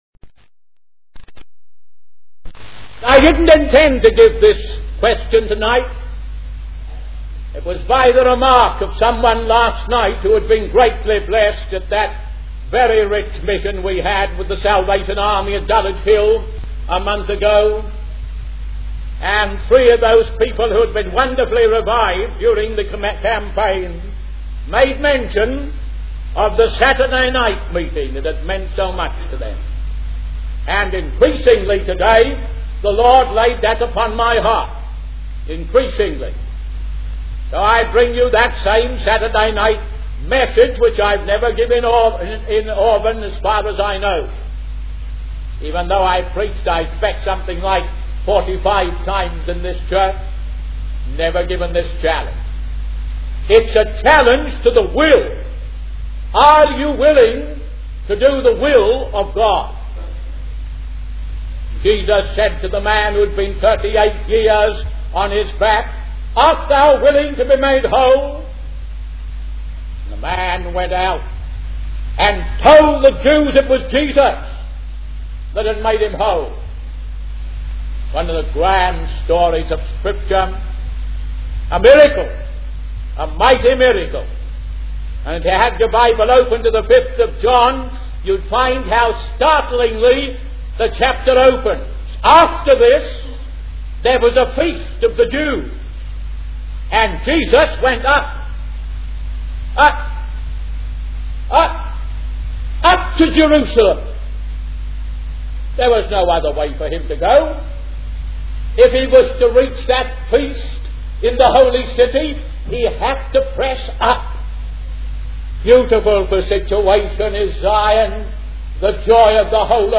In this sermon, the speaker discusses the concept of the 'Triangle of Life' that exists in every town and city.